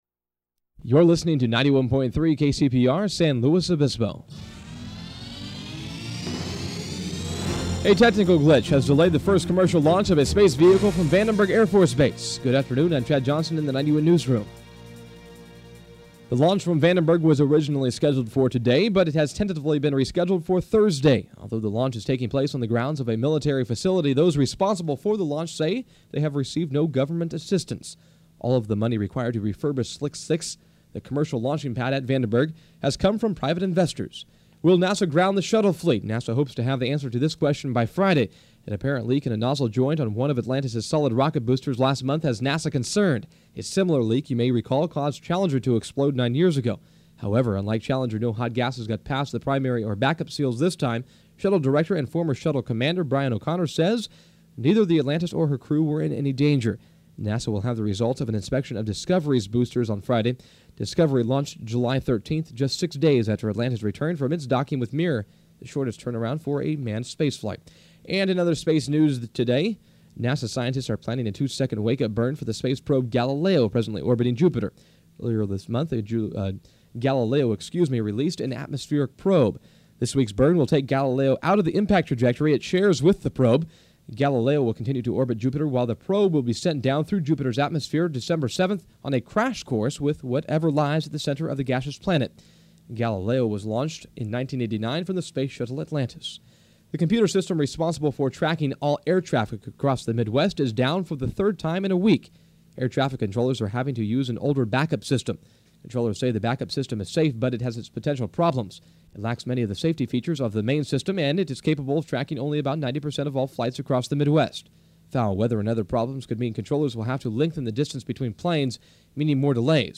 5pm News
Form of original Audiocassette